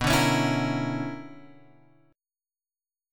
BmM11 chord {7 5 x 6 5 6} chord